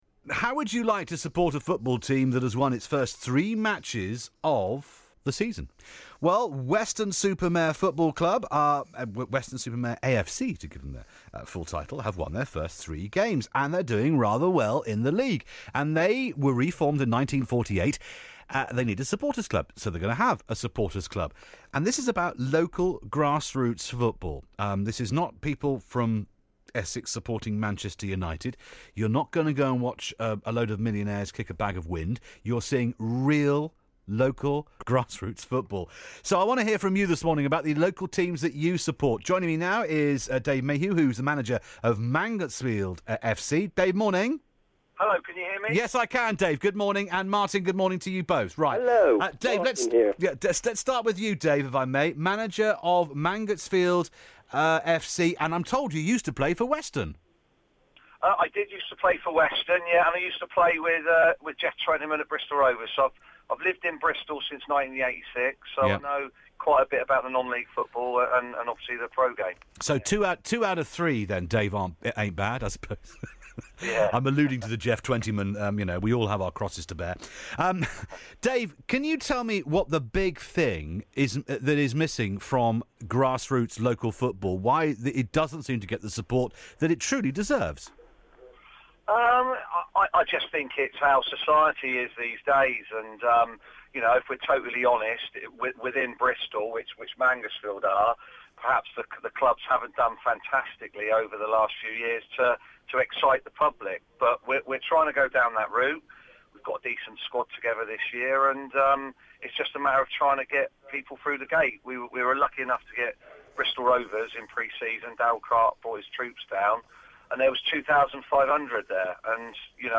being interviewed by BBC Radio Bristol on Tuesday 16 August regarding crowds in non league football and clubs commercial activities